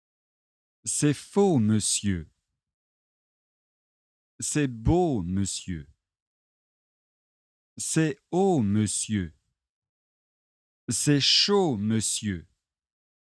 Exercice prononciation française : voyelles antérieures et postérieures